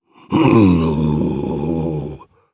Phillop-Vox_Casting2_jp.wav